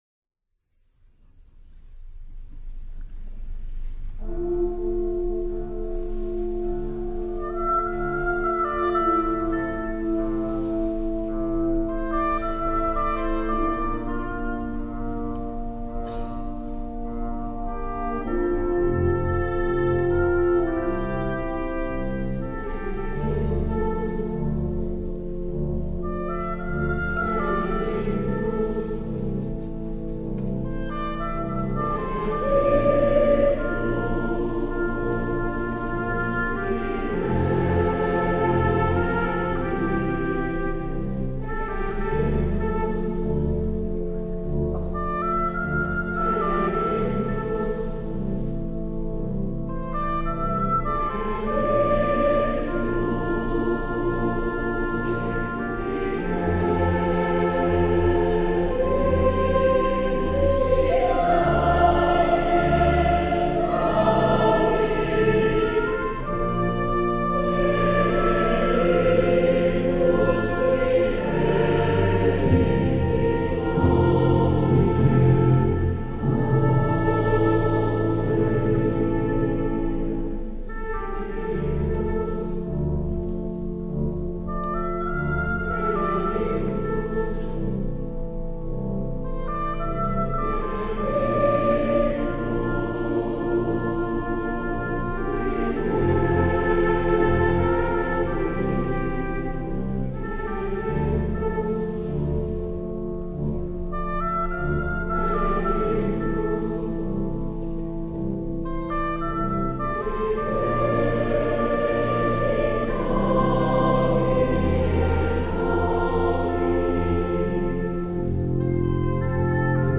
S. Gaudenzio church choir Gambolo' (PV) Italy
19 Dicembre - Concerto di Natale
La registrazione audio del Concerto (MP3)